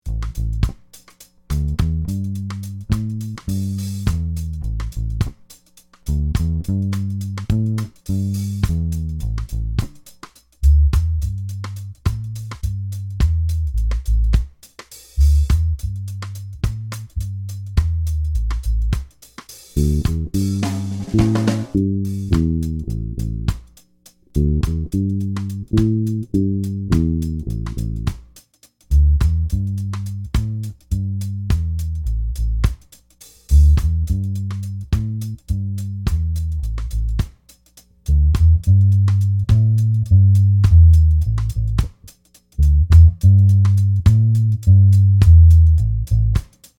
The Mu-Tron SUPER-FREQ is a dual state variable filter with multiple routing and actuating options.